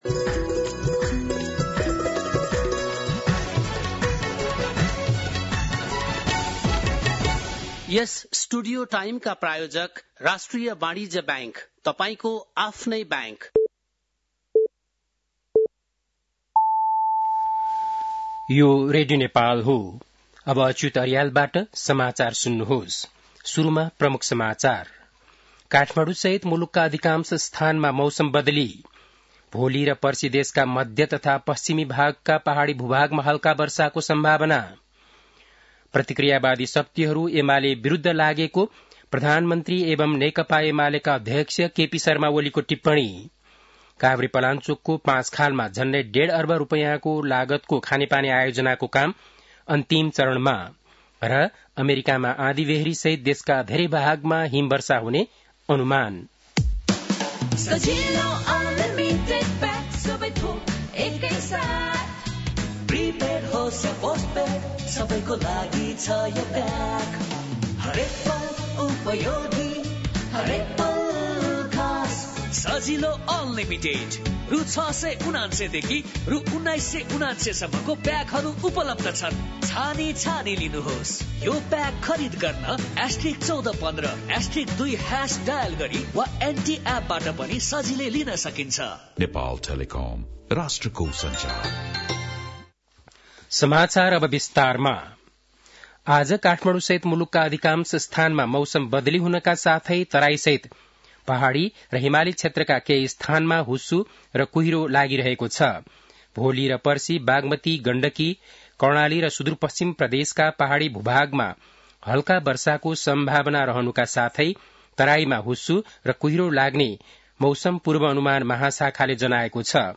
बेलुकी ७ बजेको नेपाली समाचार : २२ पुष , २०८१
7-PM-Nepali-NEWS-9-21.mp3